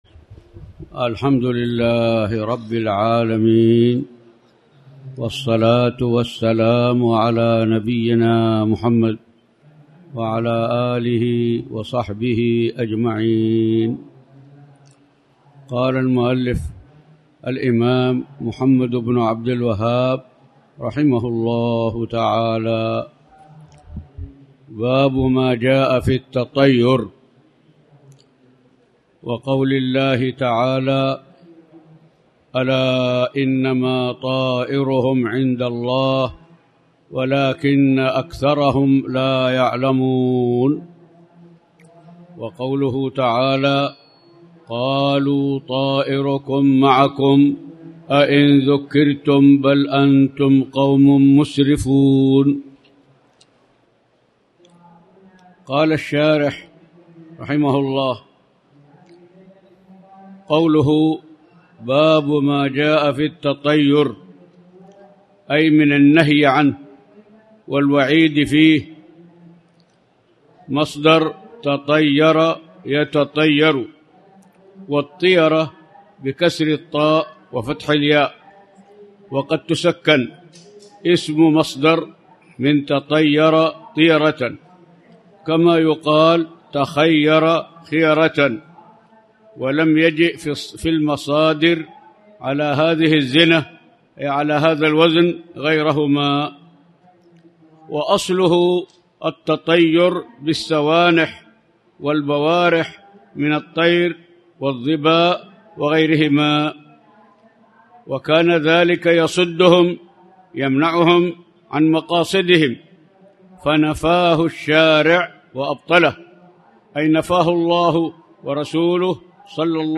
تاريخ النشر ٢٥ محرم ١٤٣٩ هـ المكان: المسجد الحرام الشيخ